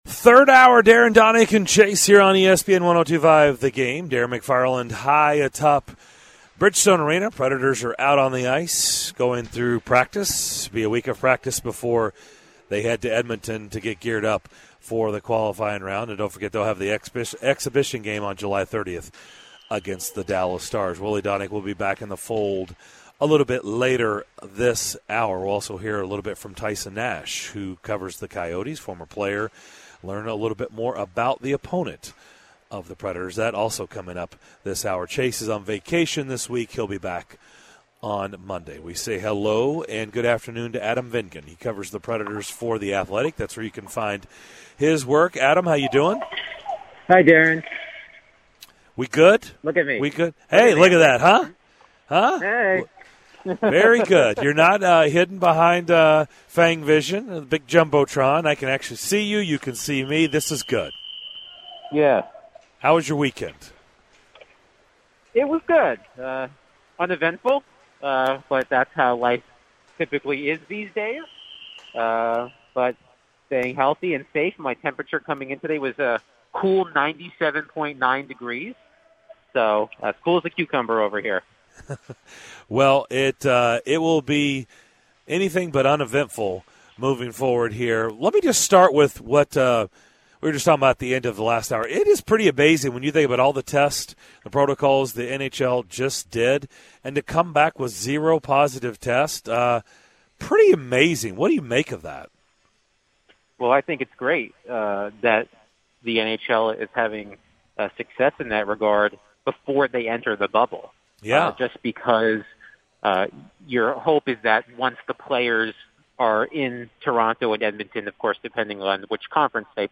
live at Bridgestone Arena to discuss Preds training camp the line up shifts as they prepare for the Coyotes.